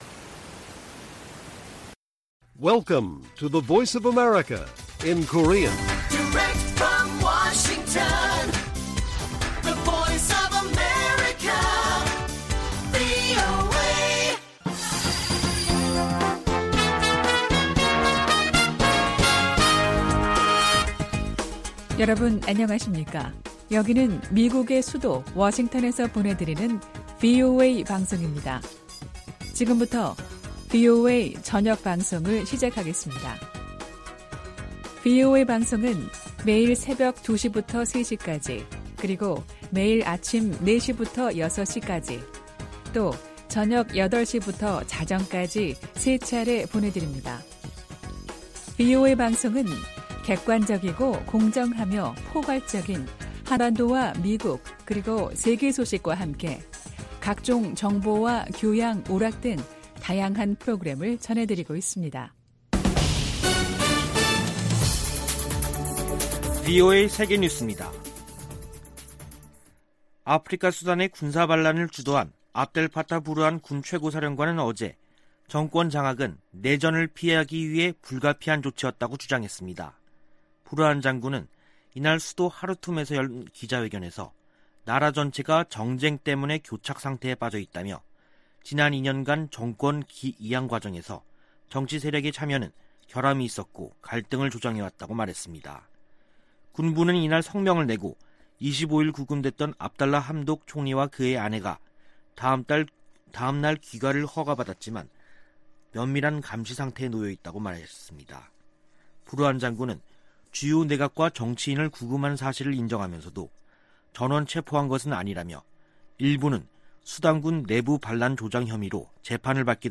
VOA 한국어 간판 뉴스 프로그램 '뉴스 투데이', 2021년 10월 27일 1부 방송입니다. 대북 접근법 수행 과정에 미국과 한국의 관점이 다를 수도 있다고 제이크 설리번 미 국가안보 보좌관이 밝혔습니다. 미 국무부가 국제 해킹 대응 조직을 신설합니다. 캐나다 인권단체가 한국과 미국에 이어 세 번째로 제3국 내 탈북 난민 수용 시범 프로그램을 시작합니다.